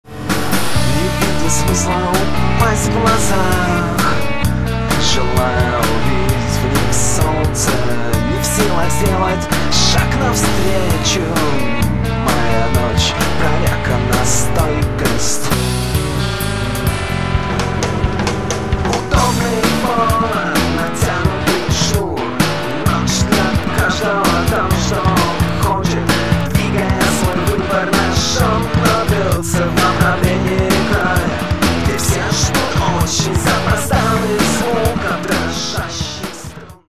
Альтернативная (2891)